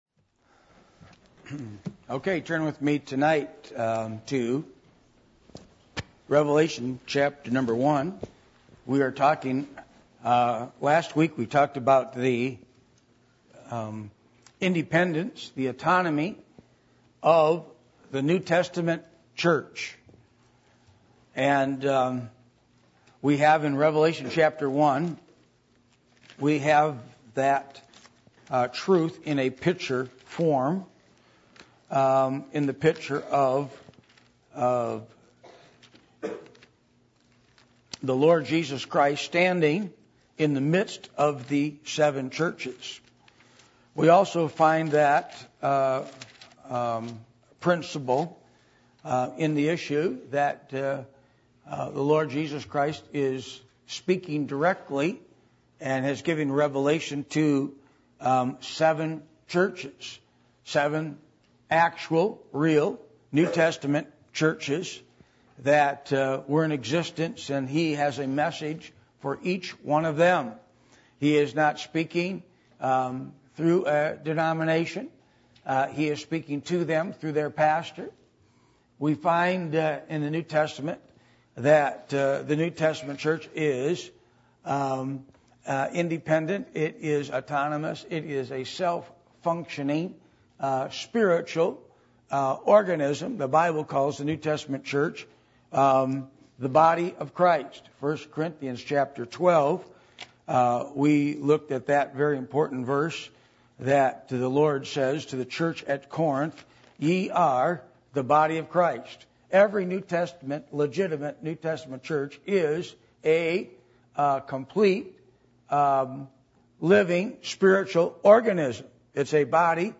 Revelation 1:1-21 Service Type: Midweek Meeting %todo_render% « The Eternal Difference How Do I Grow Through The Study Of Scripture?